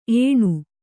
♪ ēṇu